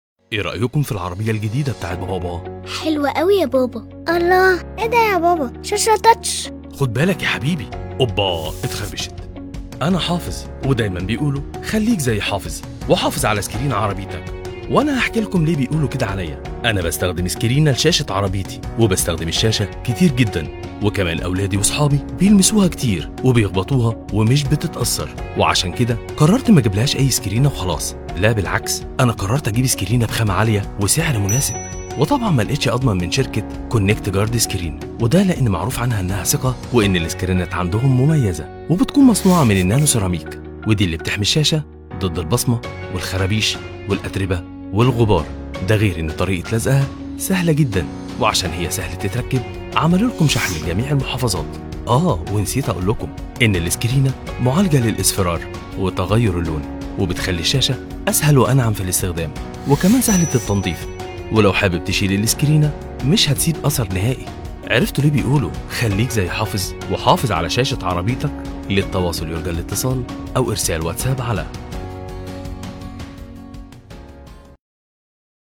Professional Arabic Standard Classic Gulf accent.